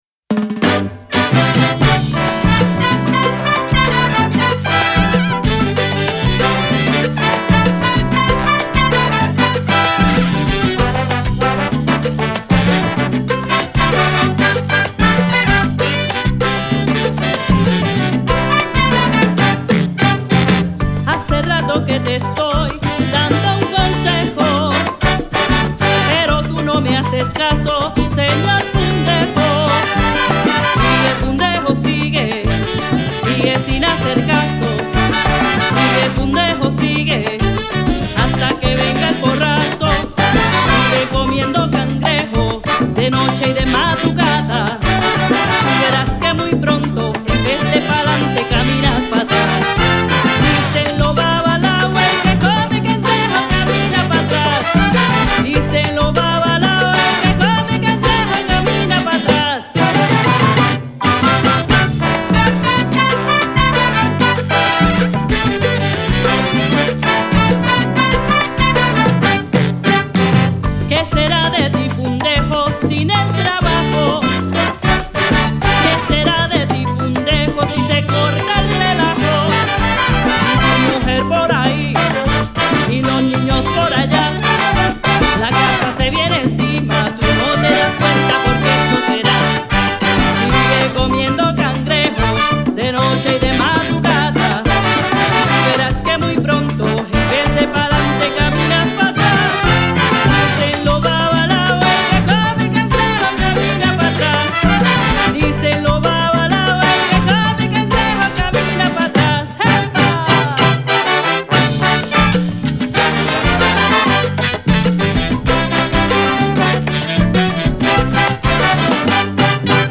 congas